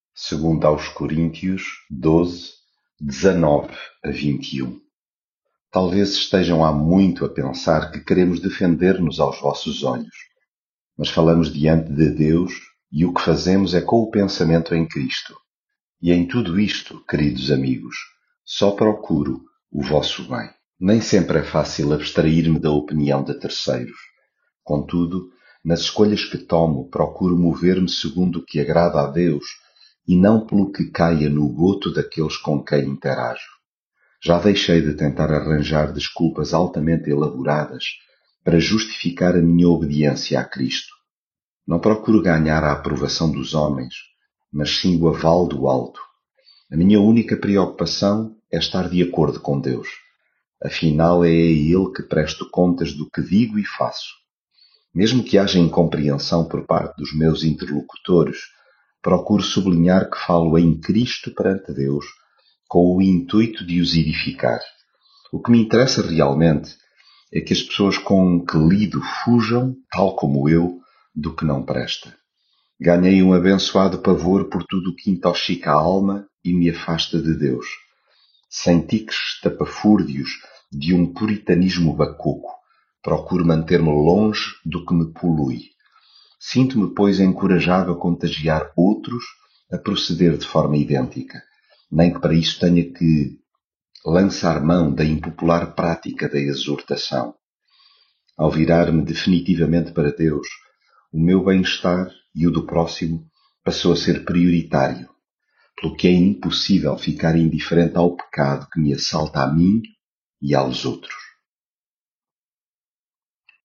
devocional coríntios
leitura bíblica